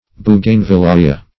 Bougainvillaea \Bou`gain*vil*l[ae]`a\, prop. n. [Named from